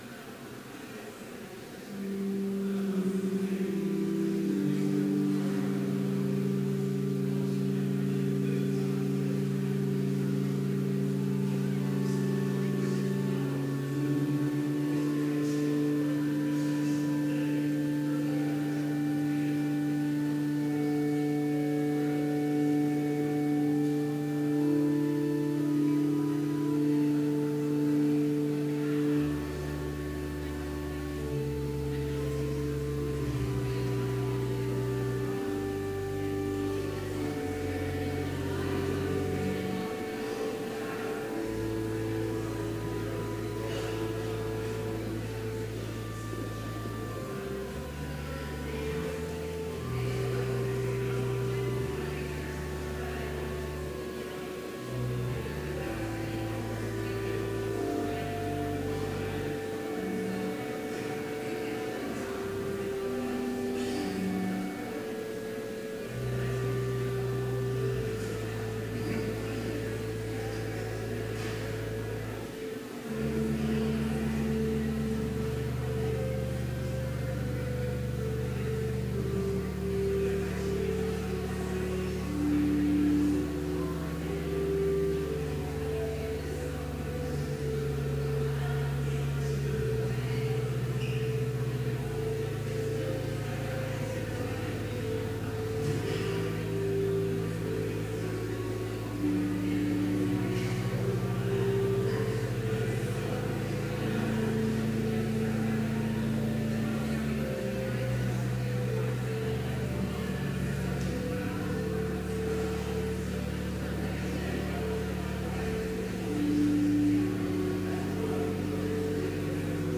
Complete service audio for Chapel - September 22, 2016
Prelude
Blessing Postlude _________ Scripture For by grace you have been saved through faith, and that not of yourselves; it is the gift of God, not of works, lest anyone should boast.